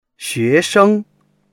xue2sheng1.mp3